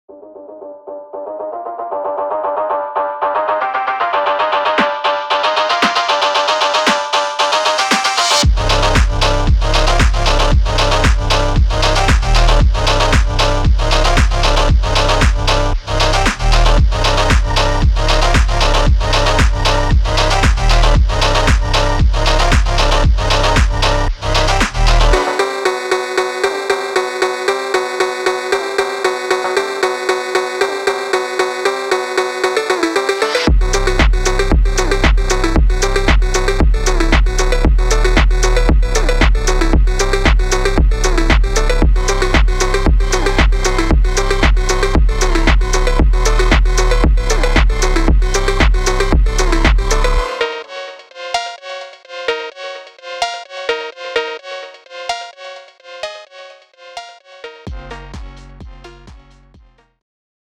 sharp & sinister melodies, pulsing drums, and booming bass.